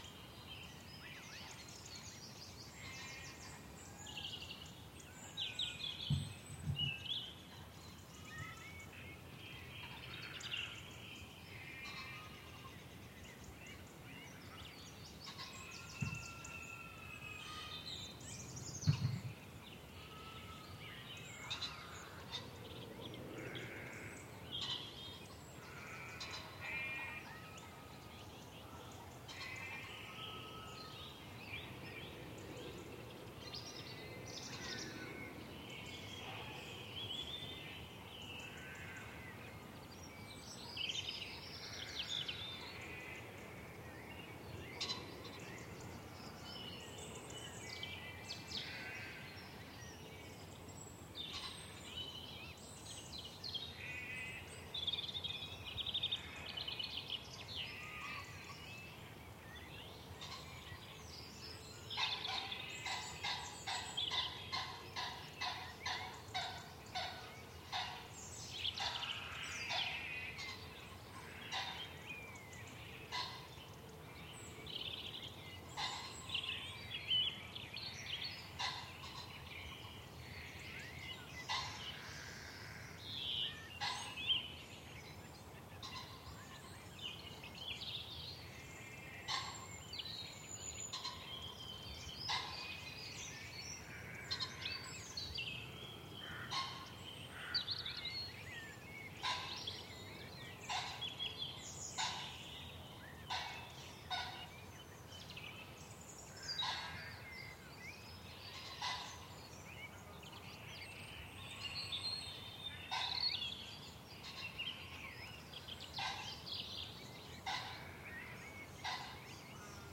气氛 " EXT 乡村多塞特 2
描述：单声道气氛录音，在英格兰东南部的一个农场。用定向话筒录制，很遗憾。
Tag: 农村 ATMOS 农场 自然 大气